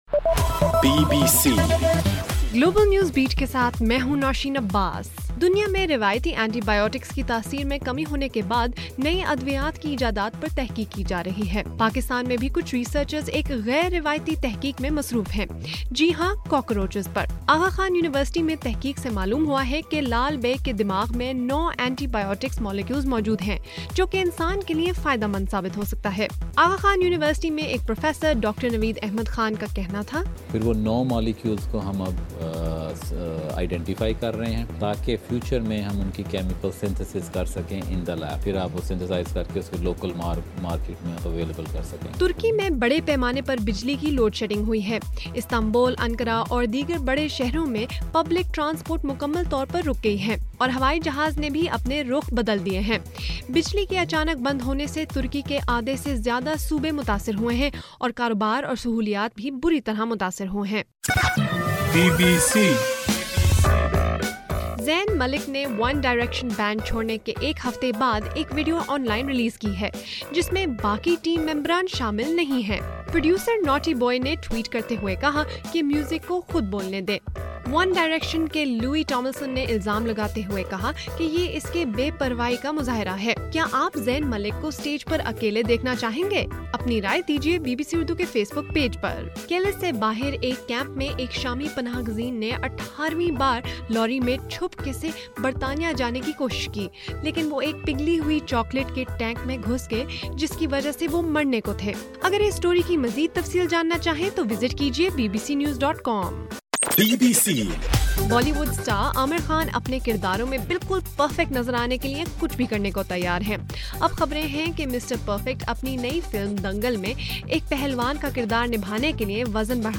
مارچ 31: رات 8 بجے کا گلوبل نیوز بیٹ بُلیٹن